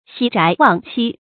徙宅忘妻 注音： ㄒㄧˇ ㄓㄞˊ ㄨㄤˋ ㄑㄧ 讀音讀法： 意思解釋： 徙：遷移；宅：住所。